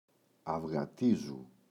αυγατίζου [avγa’tizu] – ΔΠΗ
αυγατίζου [avγa’tizu]: μεγαλώνω, αυξάνω.